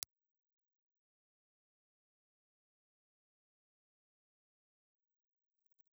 Impulse response of a Beyer M360 ribbon microphone.
Beyer_M360.wav